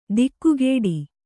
♪ dikkugēḍi